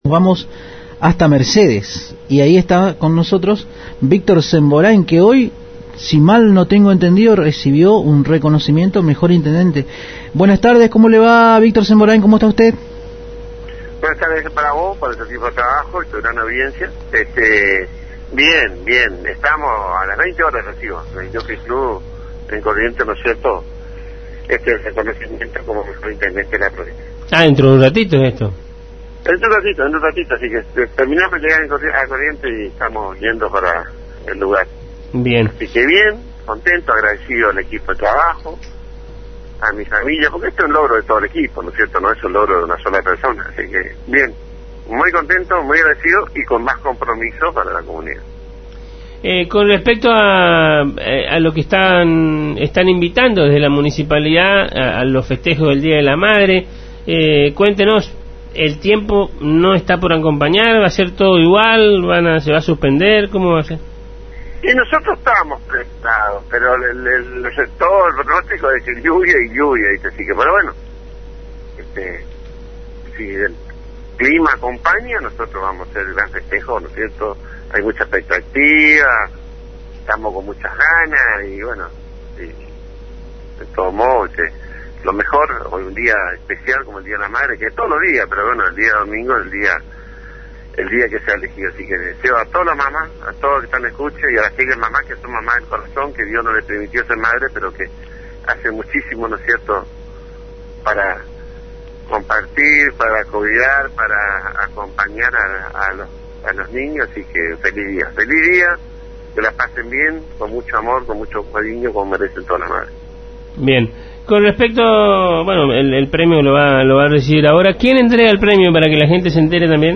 Minutos antes de ser nombrado, el intendente de Mercedes dialogaba con Agenda 970 por Radio Guaraní y expresaba su alegría por éste premio que es "un logro de todo el equipo no de una sola persona, estoy muy contento y muy agradecido y con más compromiso para la comunidad, y más trabajo".